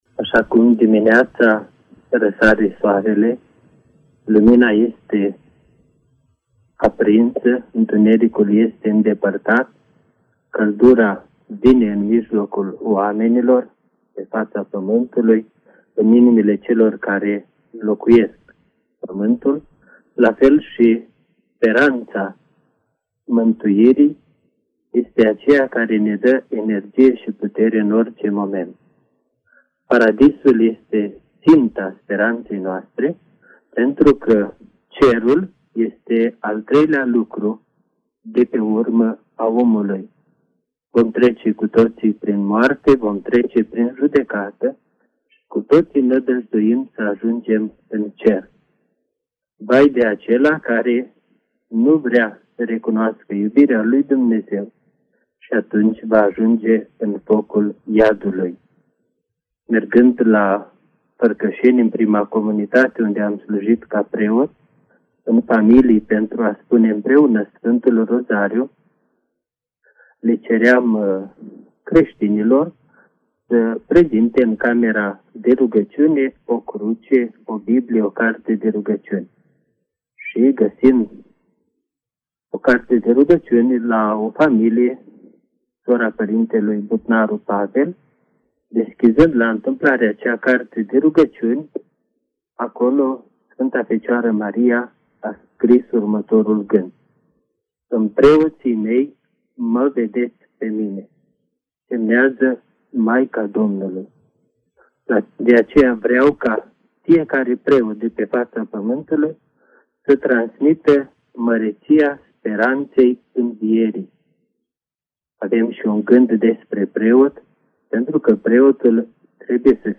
Într-un interviu pentru Radio Maria